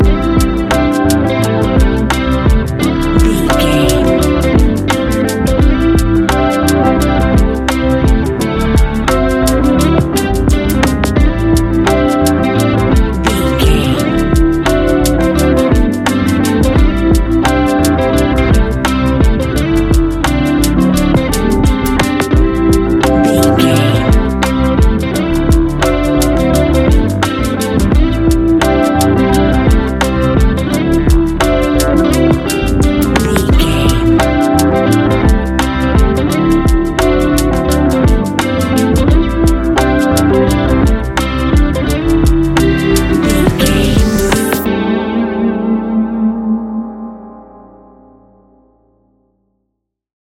Ionian/Major
laid back
Lounge
sparse
new age
chilled electronica
ambient
atmospheric
morphing